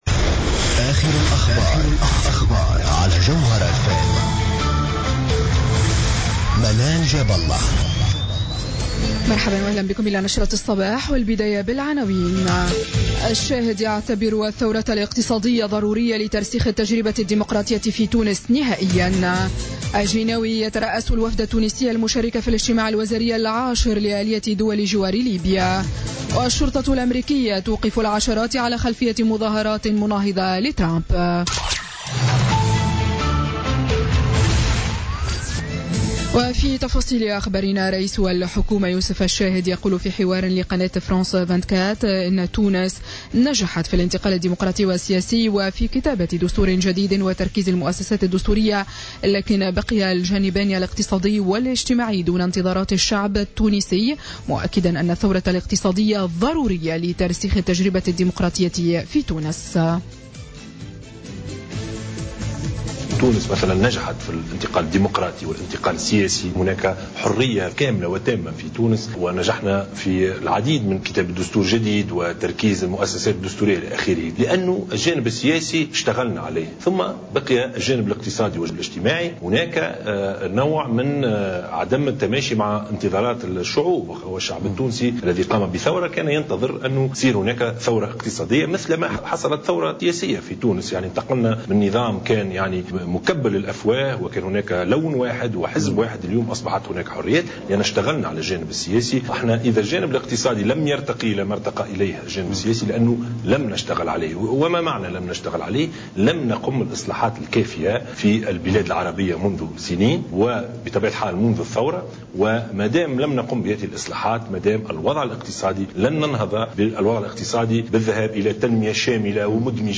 نشرة أخبار السابعة صباحا ليوم السبت 21 جانفي 2017